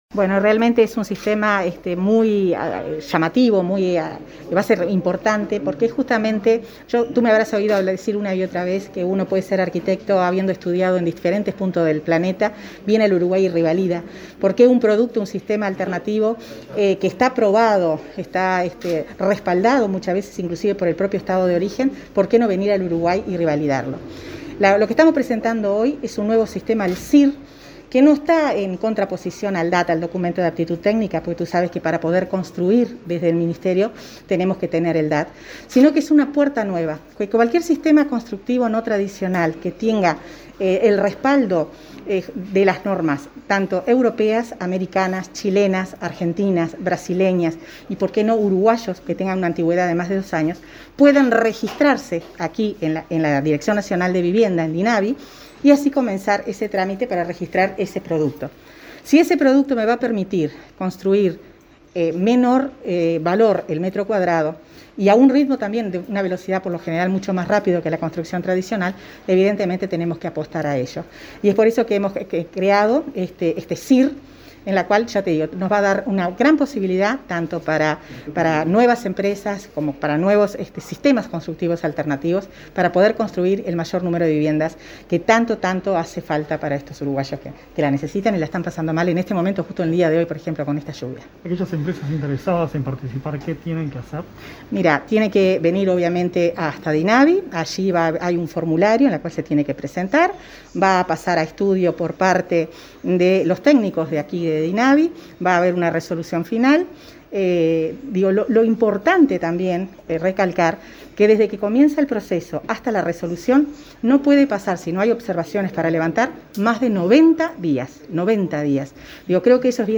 Entrevista a la ministra Irene Moreira, quien presentó programa que facilita la construcción de viviendas